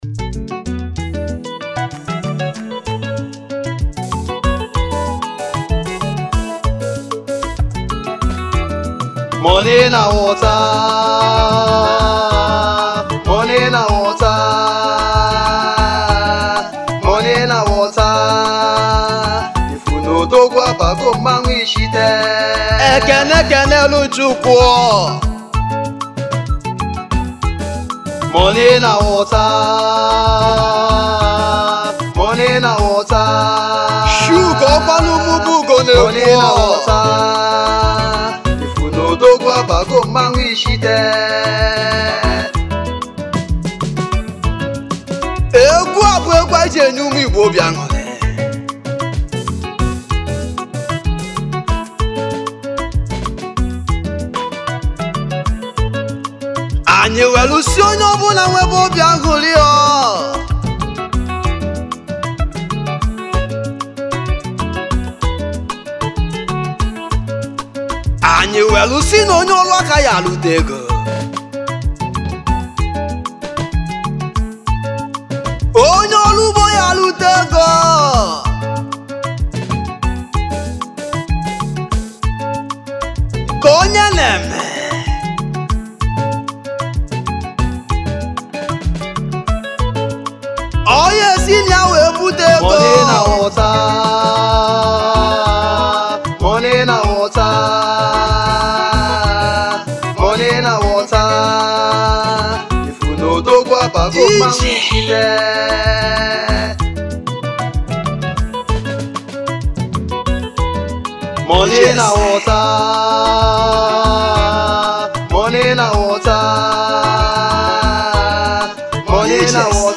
igbo highlife musician's
highlife music band
Highlife Traditional